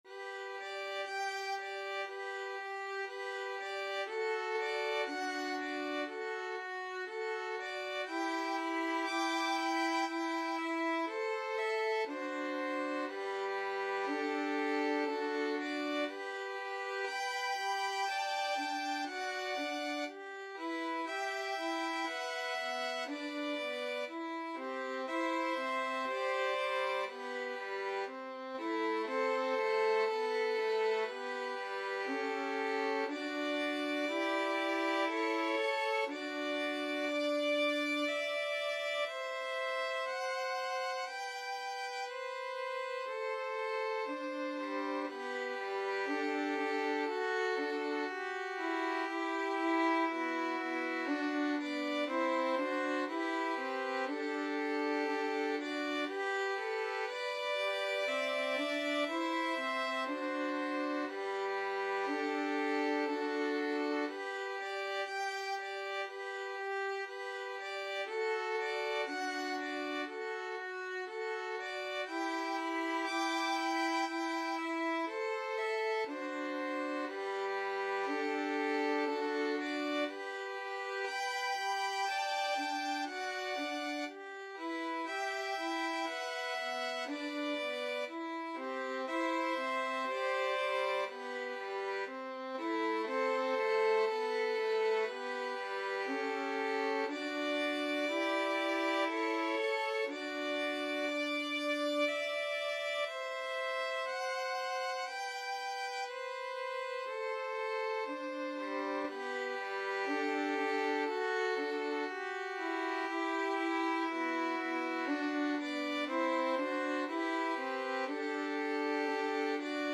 Free Sheet music for Violin Trio
Violin 1Violin 2Violin 3
G major (Sounding Pitch) (View more G major Music for Violin Trio )
4/4 (View more 4/4 Music)
Adagio = c. 60
Classical (View more Classical Violin Trio Music)